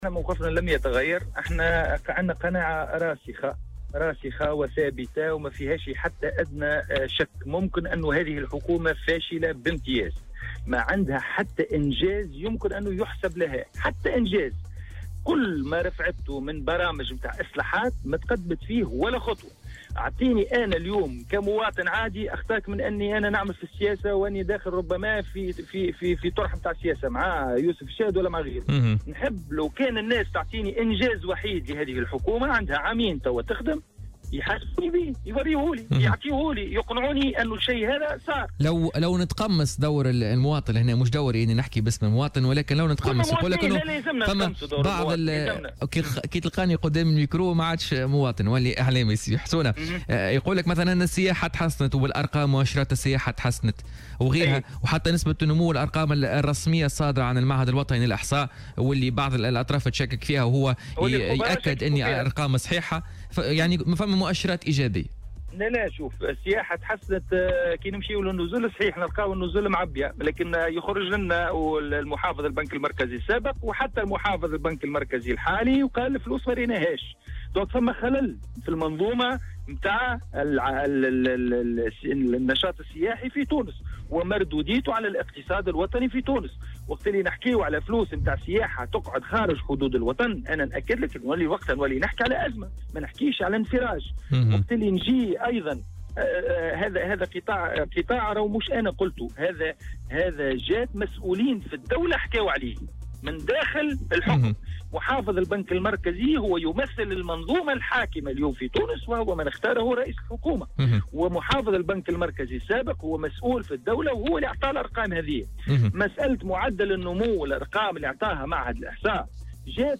اعتبر حسونة الناصفي، المتحدث باسم حركة مشروع تونس، حكومة يوسف الشاهد "حكومة فاشلة باميتاز".